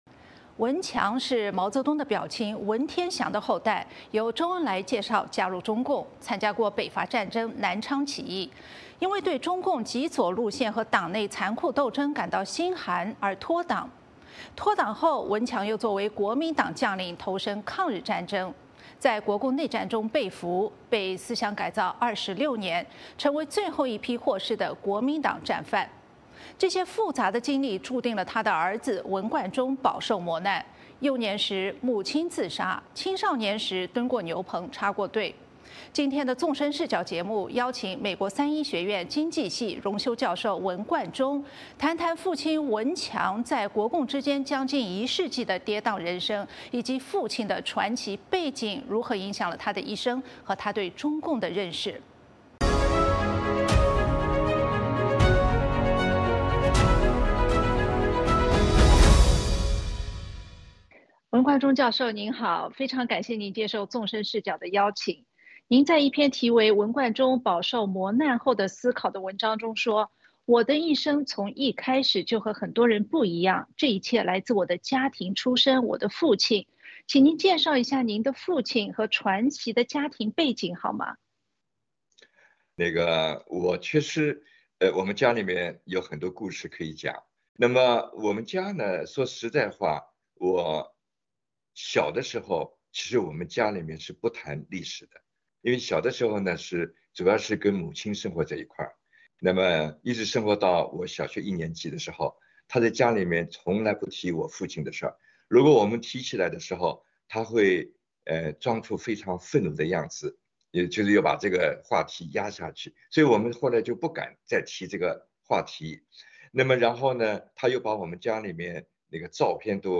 《纵深视角》节目进行一系列人物专访，受访者所发表的评论不代表美国之音的立场